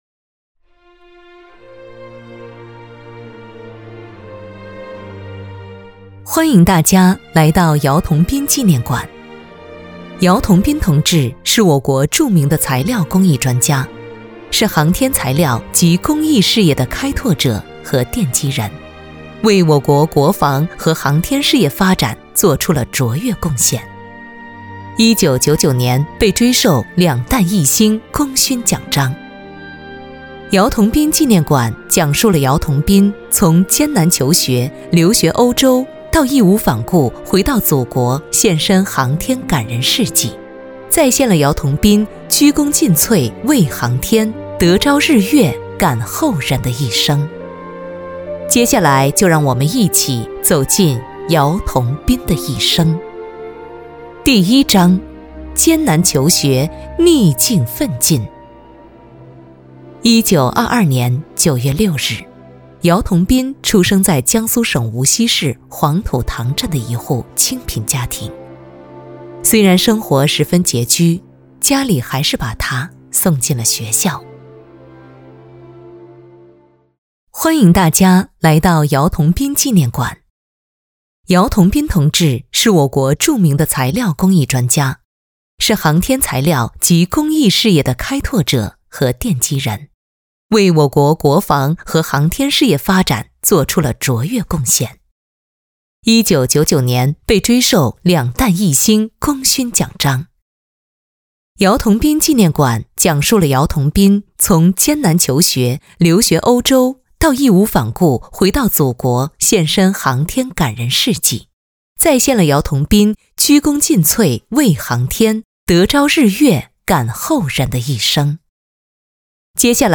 男2号（活力、时尚） | 编号:XYB002 点击更多 男2活力激情广告-迪拜之旅 请TA配音 男2-活力广告-奔驰smart 请TA配音 男2-年轻广告-生态住宅 请TA配音 男2-广告-粤菜餐厅 请TA配音 男2-专题-点燃梦想 请TA配音 男2-片花-美食生活 请TA配音 女4号（品质、知性） | 编号:XYG004 点击更多 女4-介绍-姚桐斌纪念馆 请TA配音 女4-宣传-活力阳泉 请TA配音
女4-介绍-姚桐斌纪念馆.mp3.mp3